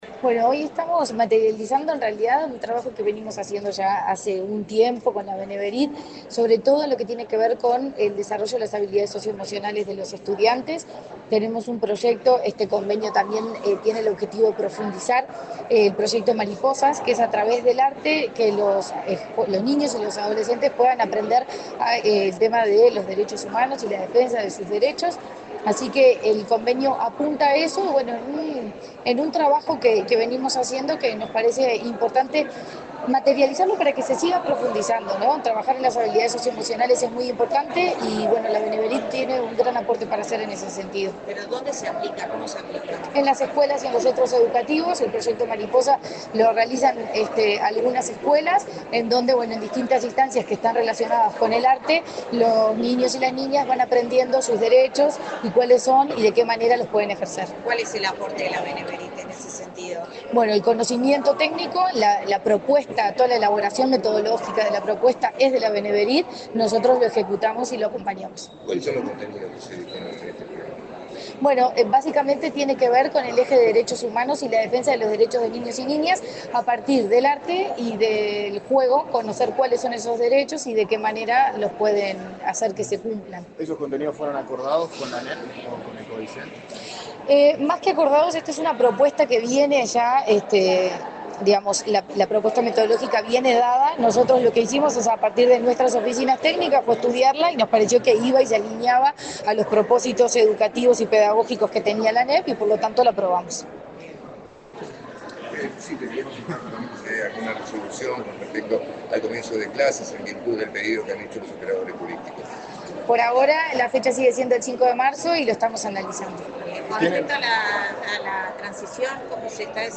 Declaraciones de la presidenta de la ANEP, Virginia Cáceres
La presidenta de la Administración Nacional de Educación Pública (ANEP), Virginia Cáceres, dialogó con la prensa, acerca de la firma de un convenio